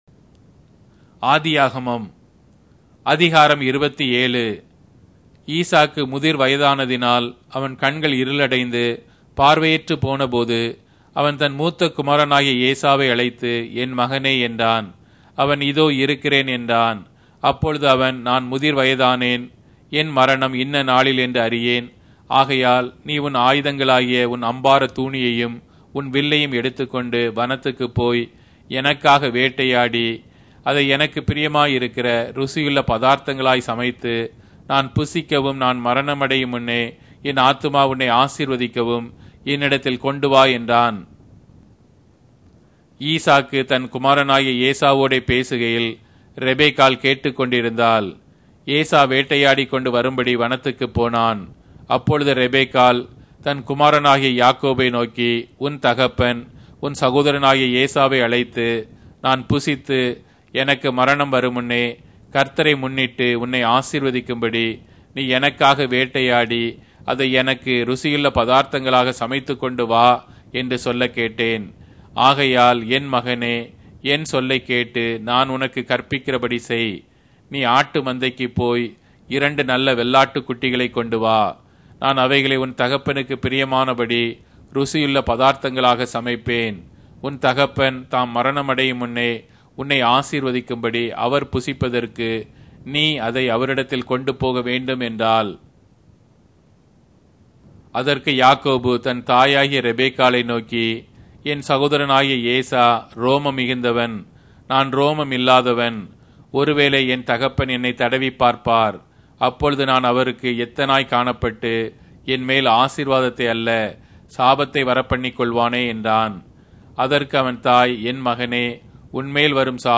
Tamil Audio Bible - Genesis 7 in Lxxrp bible version